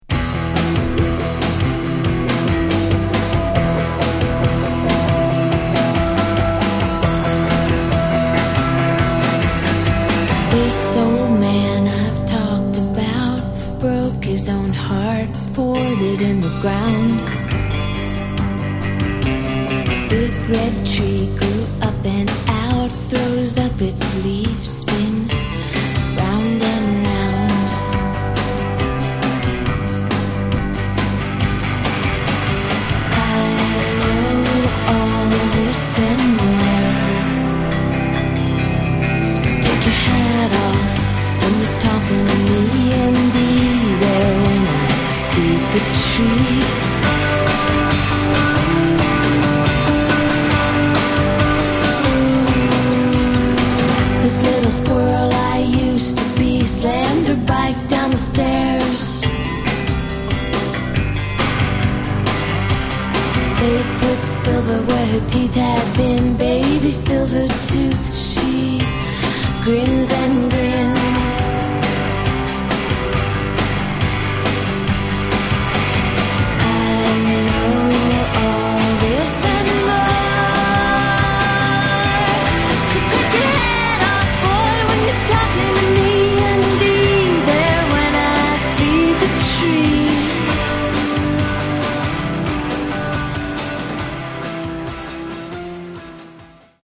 Genre: Punk/Grunge